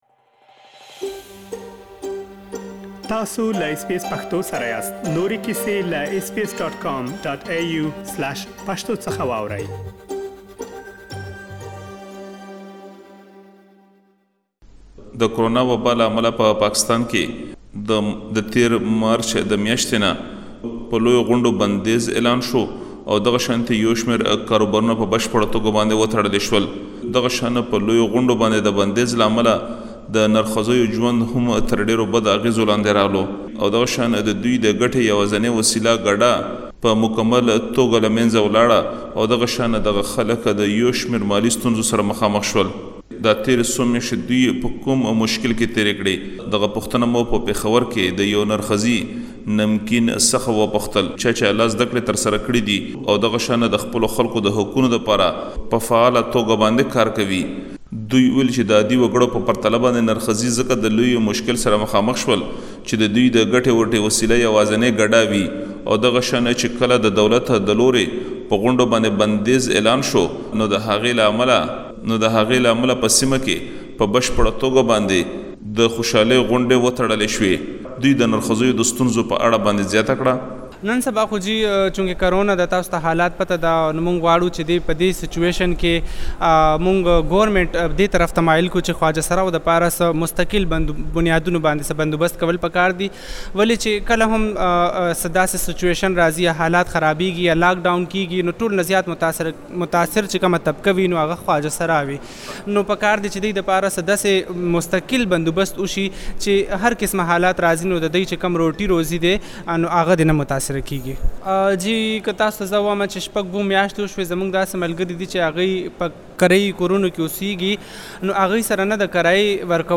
له ځينو نرښځو سره خبرې کړي او د هغوی ستونزې يې راخيستي چې دا ټول تاسې په رپوټ کې اوريدلی شئ.